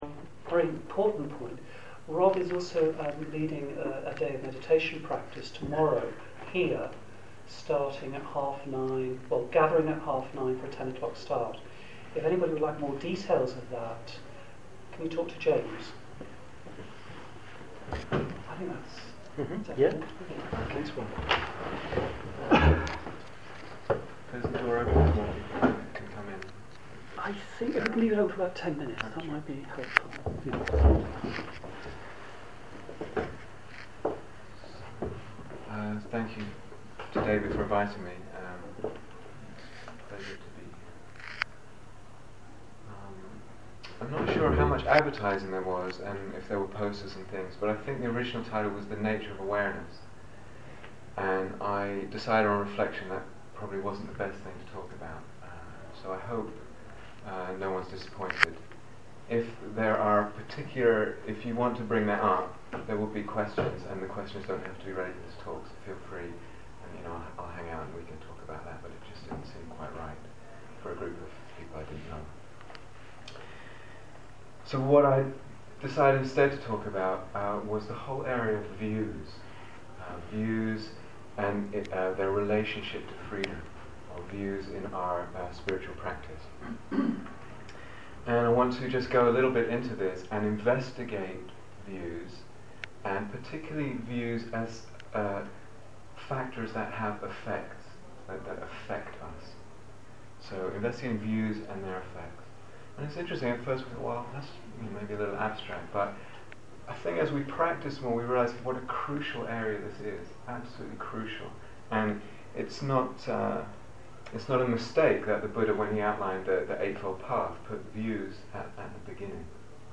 [Transcriber's note: the recording starts with announcements from the organizers of the retreat, omitted in this transcript.